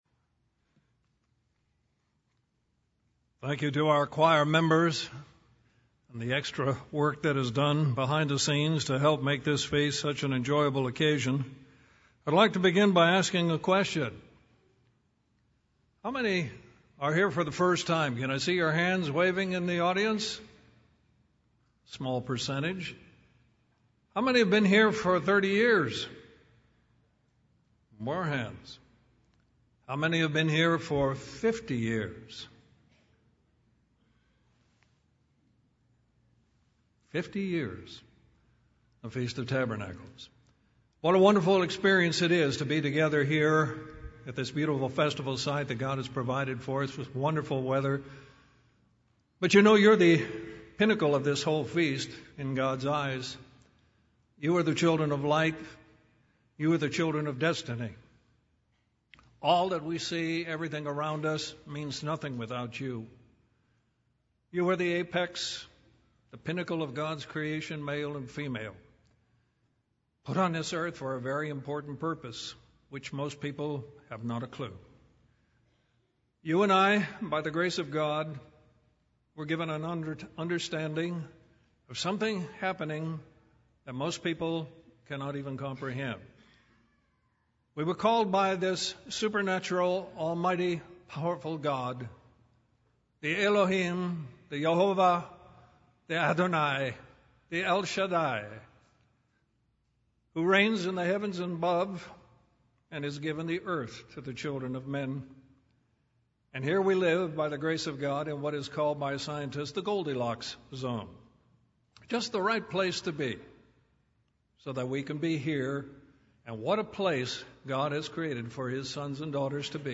This sermon was given at the Panama City Beach, Florida 2014 Feast site.